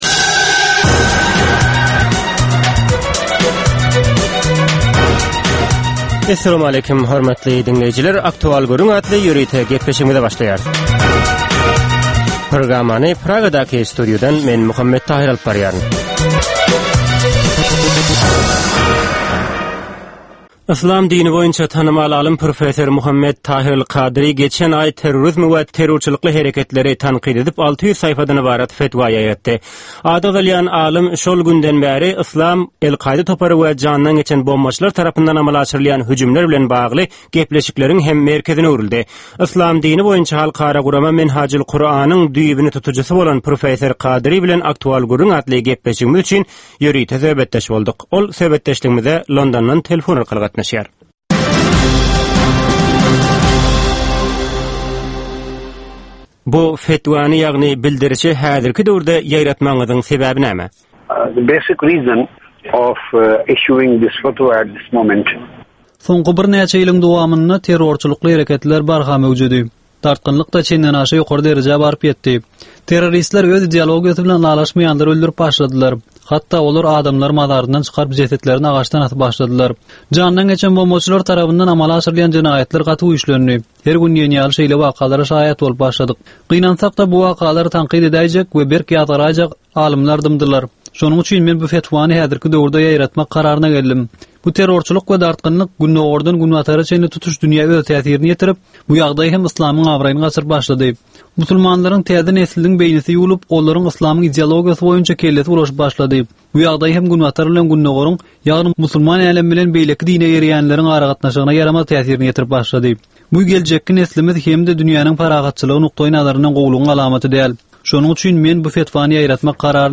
Hepdäniň dowamynda Türkmenistanda ýa-da halkara arenasynda ýüze çykan, bolup geçen möhüm wakalar, meseleler barada anyk bir bilermen ýa-da synçy bilen geçirilýän ýörite söhbetdeşlik. Bu söhbetdeşlikde anyk bir waka ýa-da mesele barada synçy ýa-da bilermen bilen aktual gürründeşlik geçirilýär we meseläniň dürli ugurlary barada pikir alyşylýar.